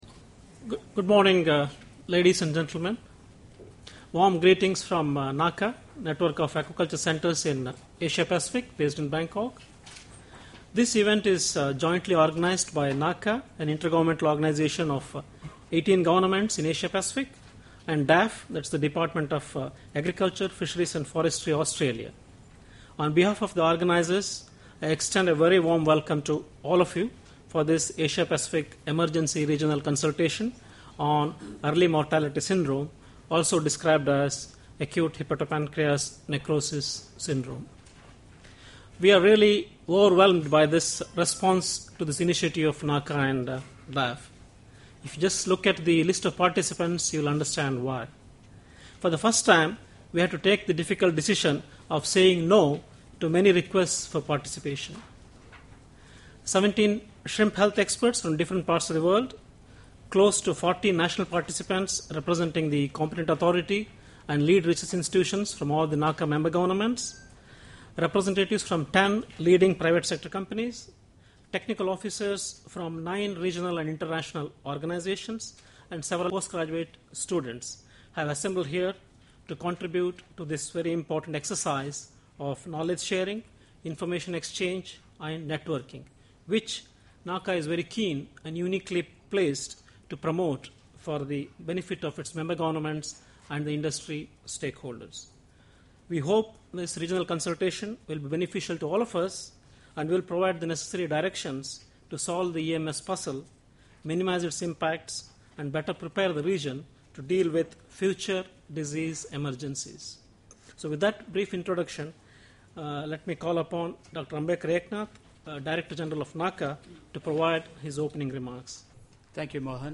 Opening remarks
Opening remarks at the Emergency Regional Consultation on Acute Hepatopancreatic Necrosis Syndrome.